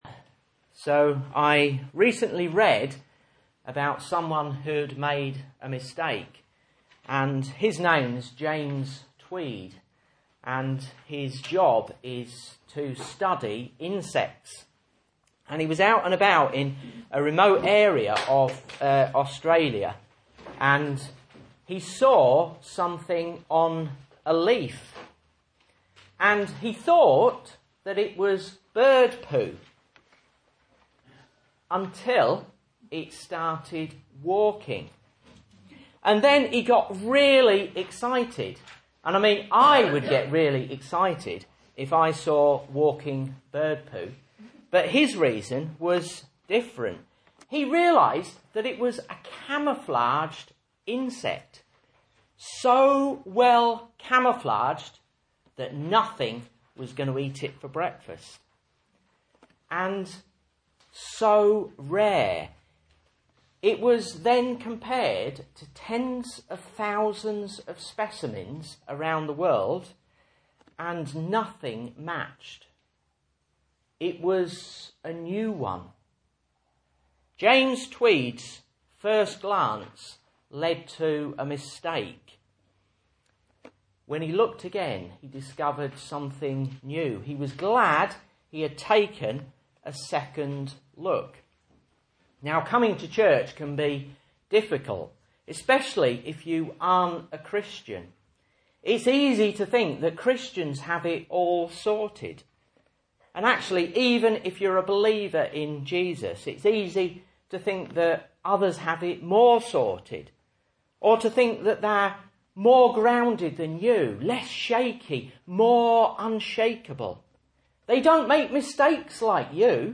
Message Scripture: 1 Corinthians 15:1-4